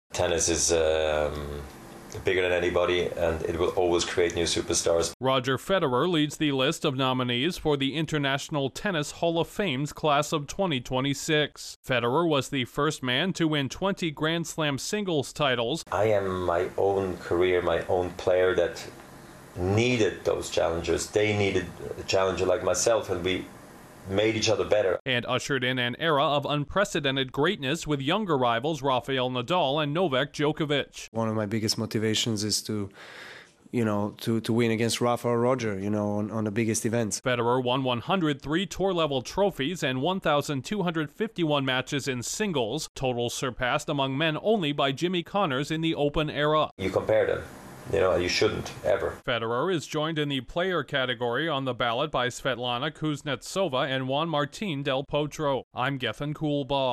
One of tennis' most iconic players is next in line to be enshrined among the sport's all-time greats. Correspondent